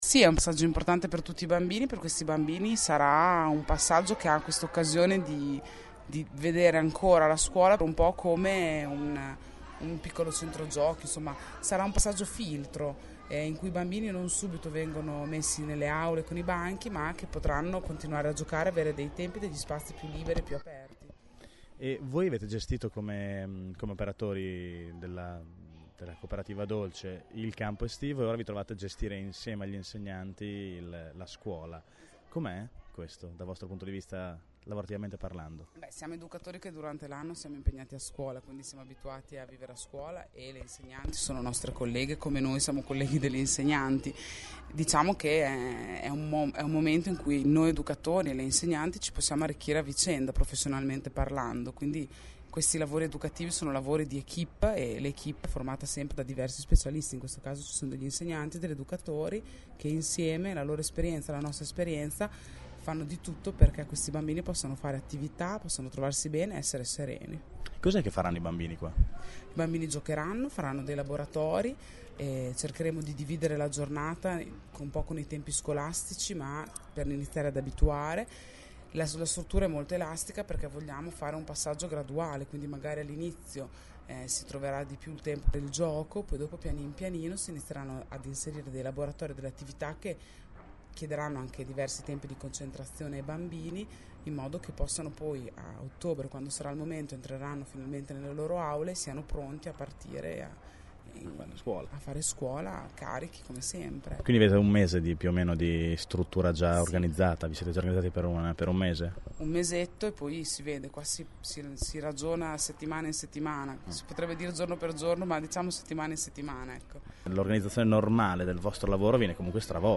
Ecco alcune delle voci raccolte questa mattina dai nostri inviati.